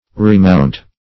Remount \Re*mount"\ (r?-mount"), v. t. & i.